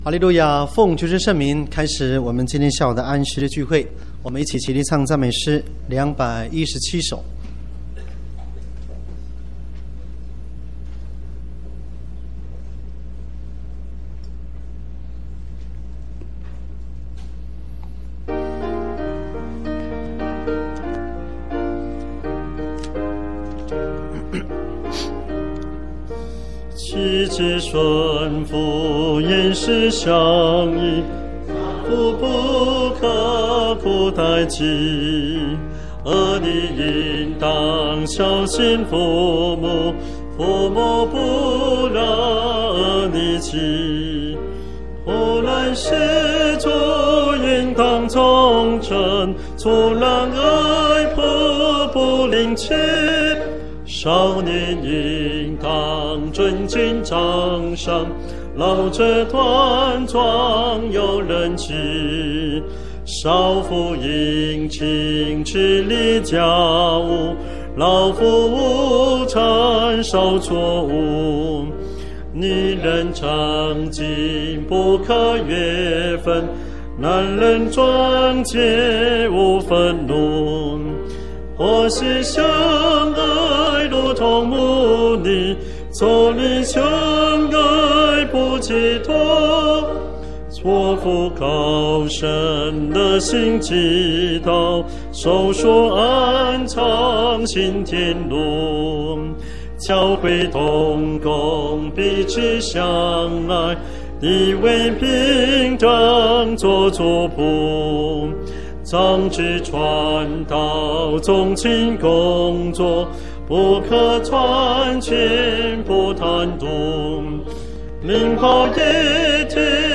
婚姻專題講道